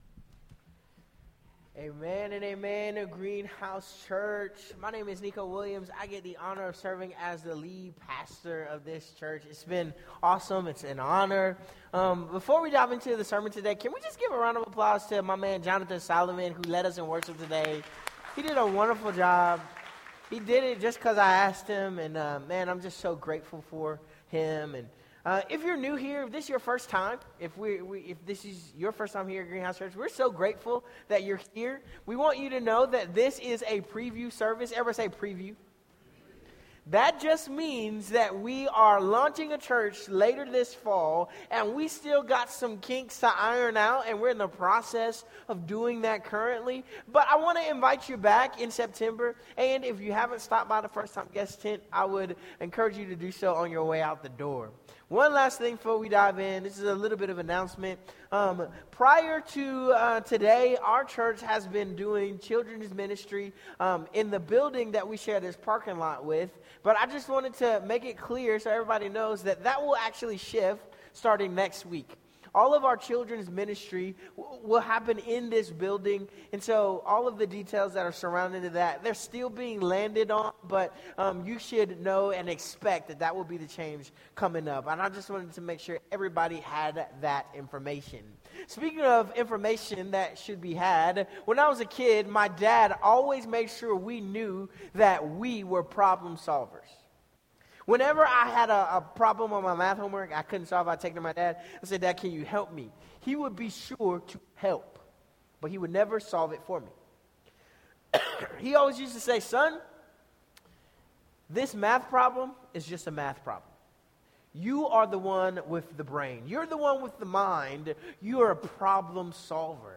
This sermon was delivered on June 1st 2025 at Greenhouse Church,